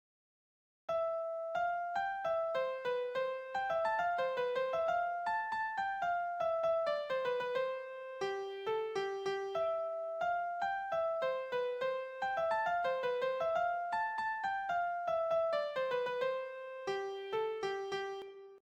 583g3. fast-Haydn 260